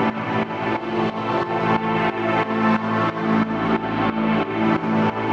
GnS_Pad-dbx1:8_90-A.wav